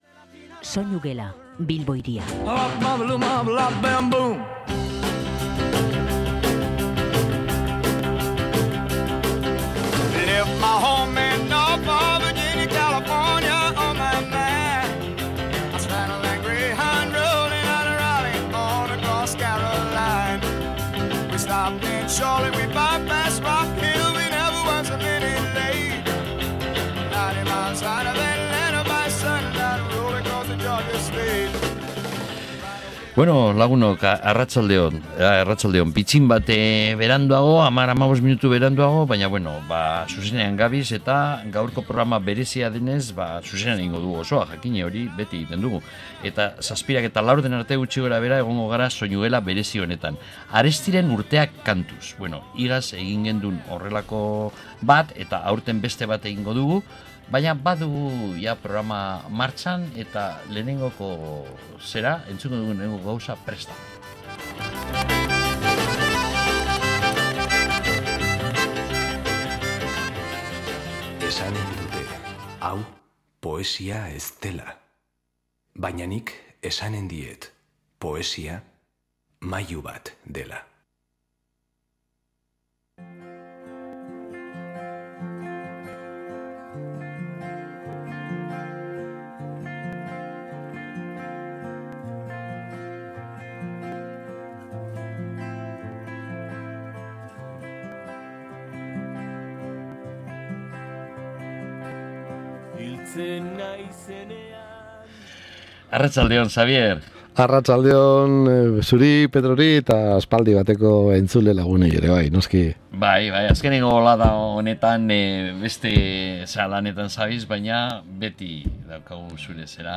musika ugari entzun dugu